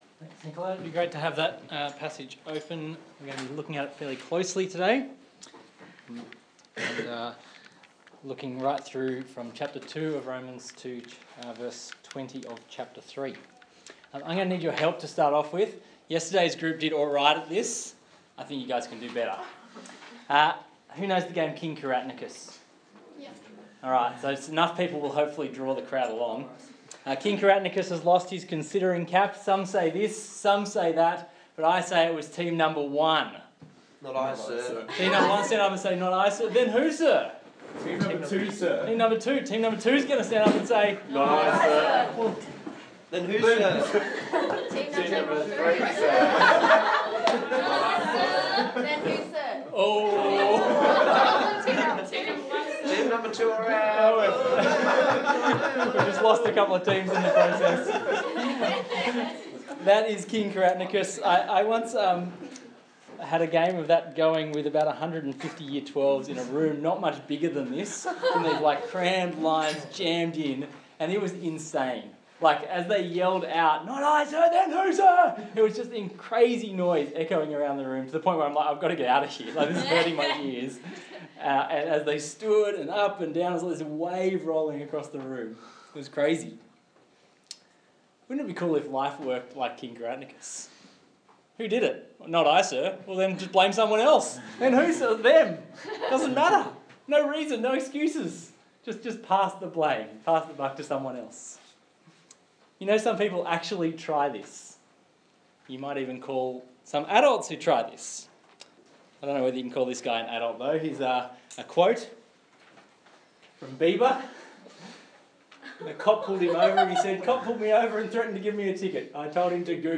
Gutter to Glory Passage: Romans 2:1-3:20 Talk Type: Bible Talk Bible Text